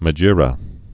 (mə-jîrə)